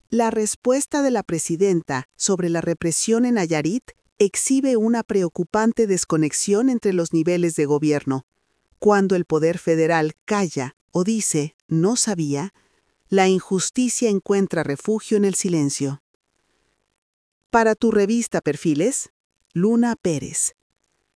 Durante su conferencia matutina del lunes pasado, la presidenta Claudia Sheinbaum Pardo fue cuestionada por un reportero sobre la represión ocurrida en Nayarit, donde policías armados desalojaron a trabajadores del Sindicato Único de Trabajadores al Servicio del Estado y Municipios (SUTSEM).
LA PREGUNTA DEL REPORTERO Y LA RESPUESTA PRESIDENCIAL